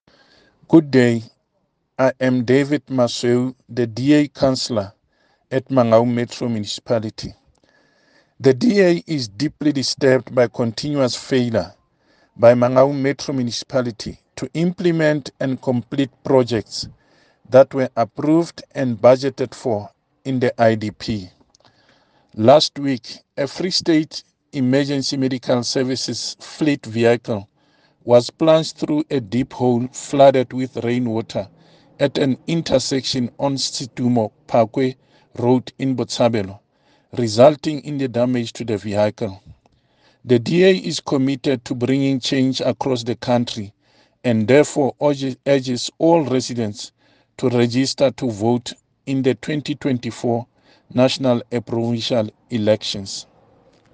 Sotho soundbites by Cllr Moshe Lefuma.